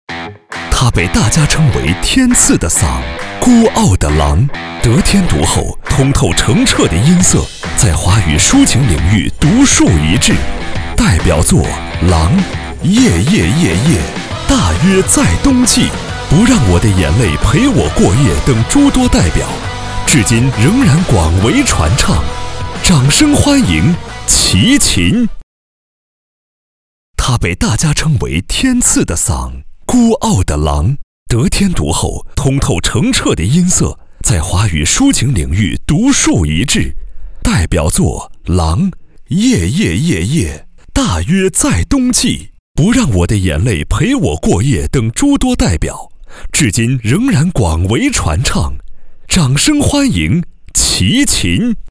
男4号（厚重、大气）
男4-综艺出场vcr
男4-综艺出场vcr.mp3.mp3